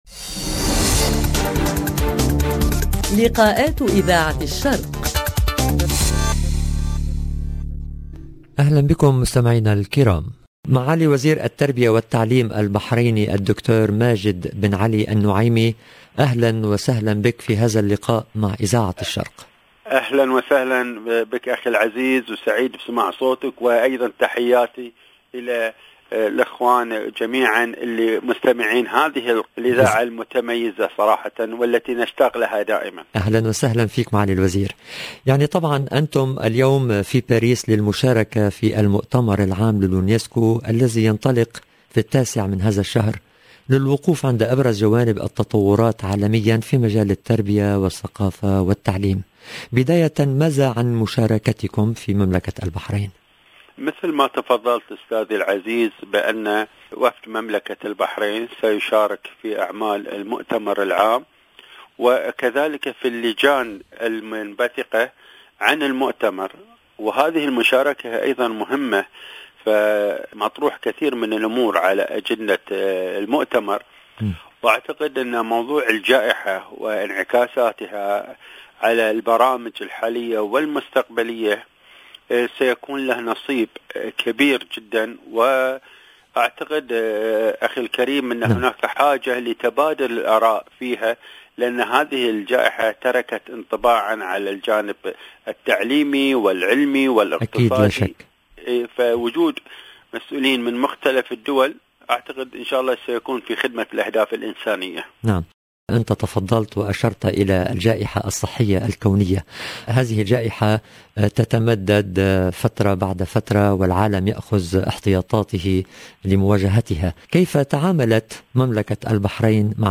Bahrein 14 novembre 2021 - 17 min 58 sec Likaat avec le ministre de l'éducation au royaume de Bahreïn Majid Al Noaimi LB Emission diffusée le 13/11/21 برنامج لقاءات اذاعة الشرق يستضيف وزير التربية والتعليم البحريني الدكتور ماجد بن علي النعيمي في اطار مشاركته في المؤتمر العام لليونسكو وتحدث لاذاعتنا عن قطاع التعليم في المملكة والتحديات التي فرضها وباء الكوفيد على القطاع وكيفية مواجهتها 0:00 17 min 58 sec